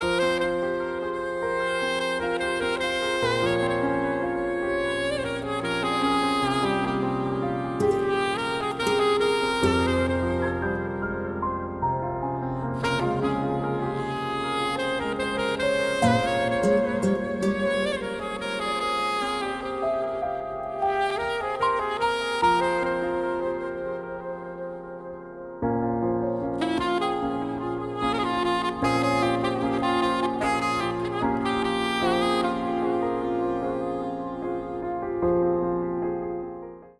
Поп Музыка
грустные # спокойные # без слов # кавер